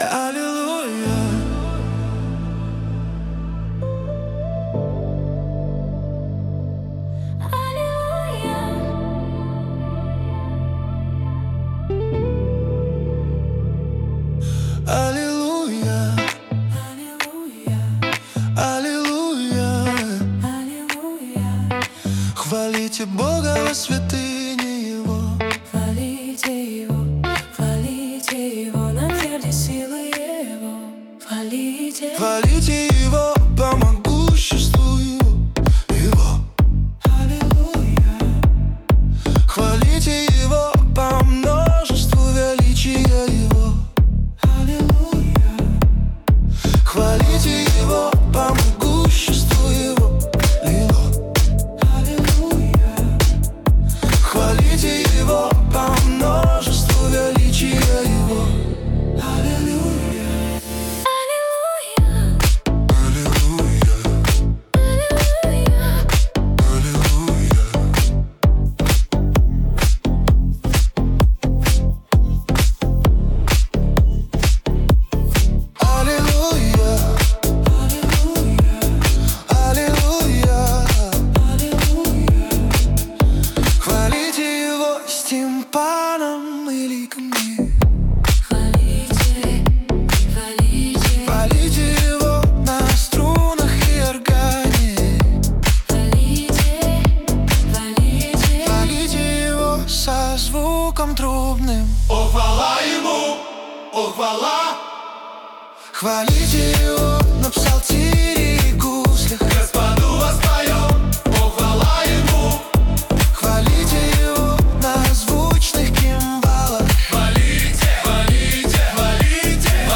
17 просмотров 36 прослушиваний 4 скачивания BPM: 130